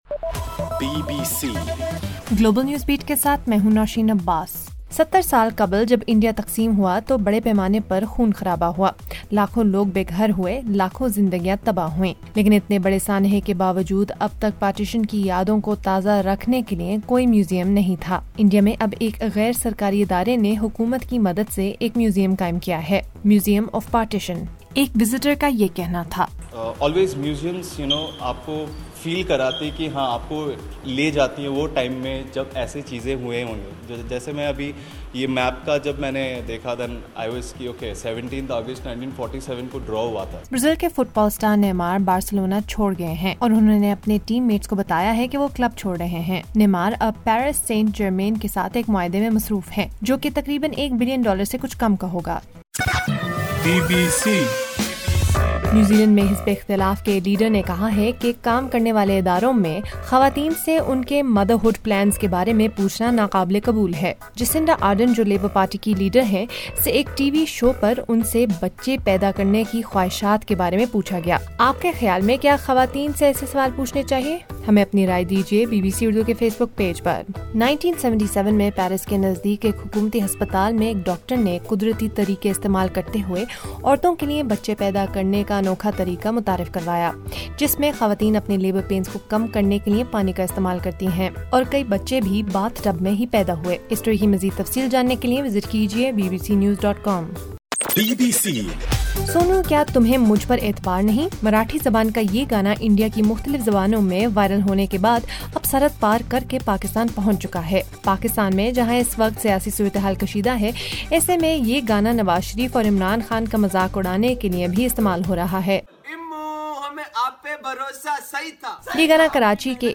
اگست 02 : شام 9 بجے کا نیوز بُلیٹن
دس منٹ کا نیوز بُلیٹن روزانہ پاکستانی وقت کے مطابق شام 5 بجے، 6 بجے اور پھر 7 بجے۔